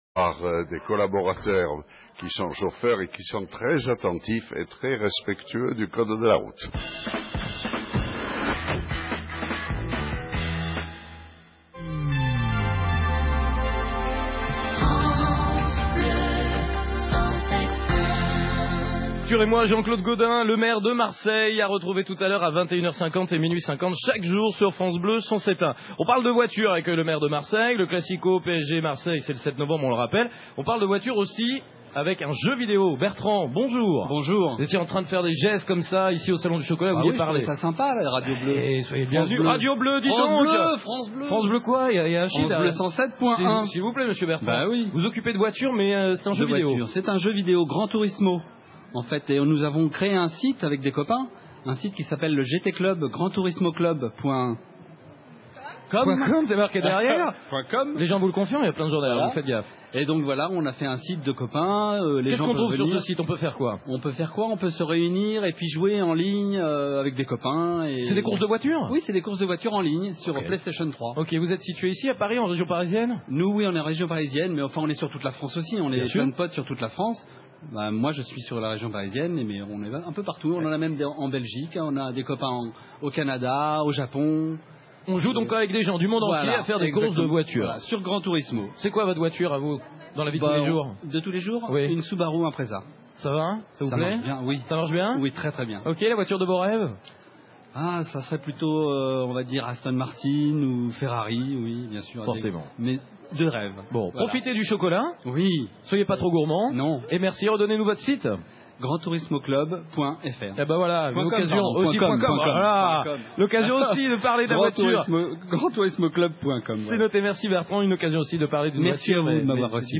Vous pouvez retrouver l'enregistrement live de la séquence ici même :